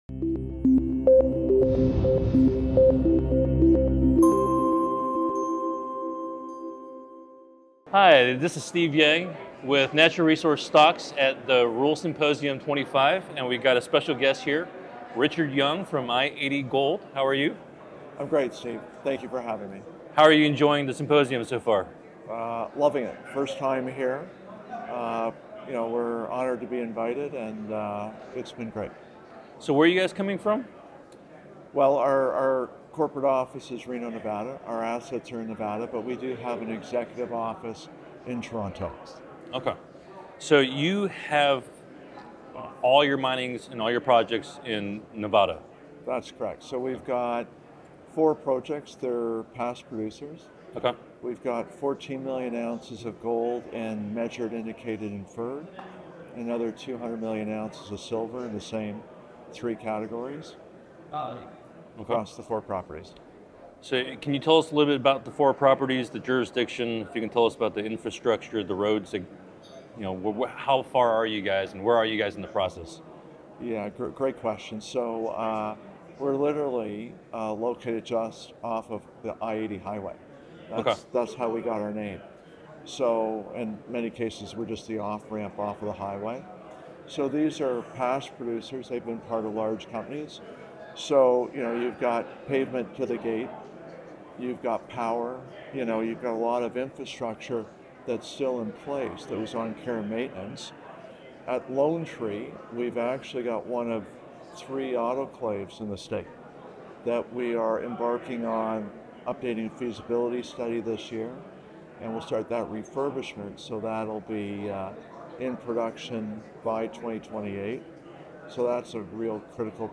at the Rural Symposium 25
interviews